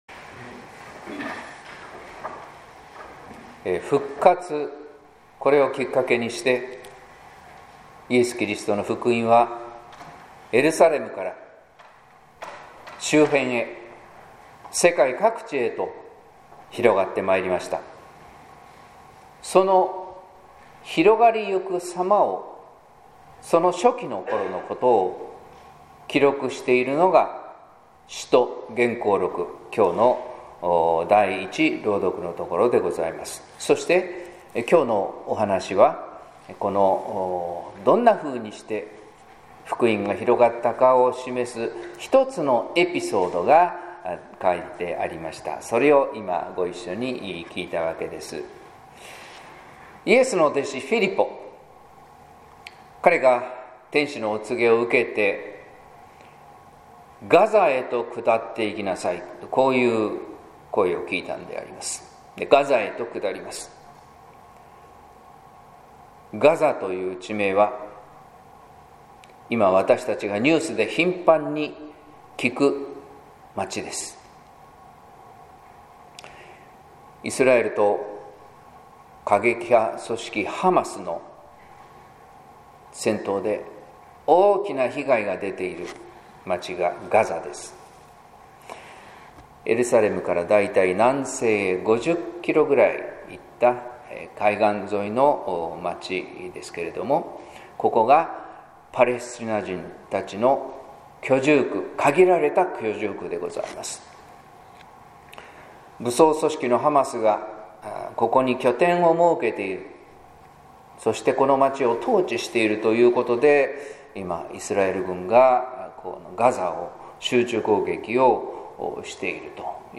説教「豊かな実を結ぶ」（音声版）